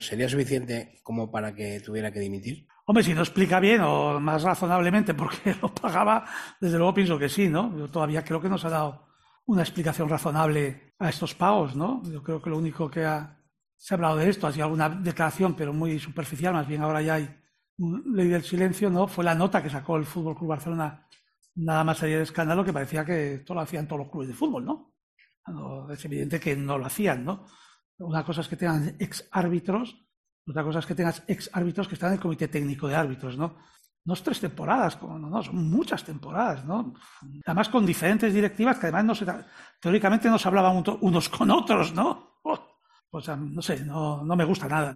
Son muchas temporadas, con diferentes directivas, que teóricamente no se hablaban, no sé, no me gusta nada", confesó Tebas en una comparecencia ante los medios, con motivo de la presentación de los límites de costes de plantilla deportiva en la sede de LaLiga en Madrid.